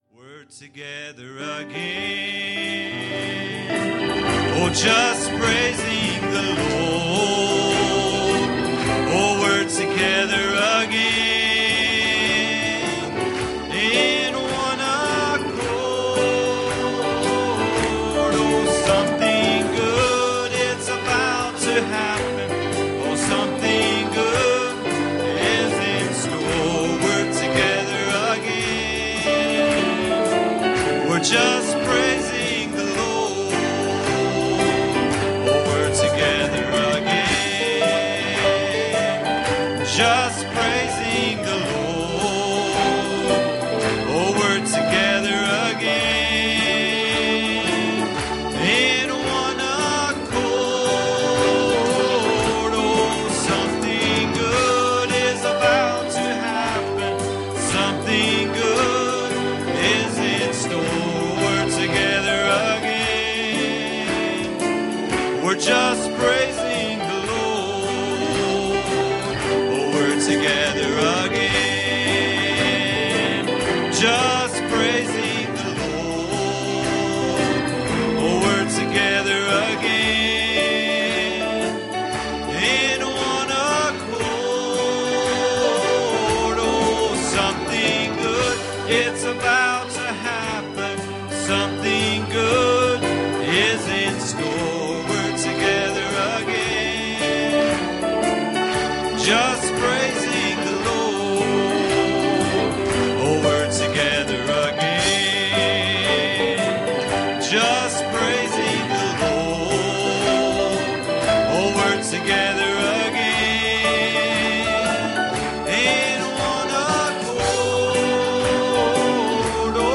Mark 7:5 Service Type: Wednesday Evening "'Our credentials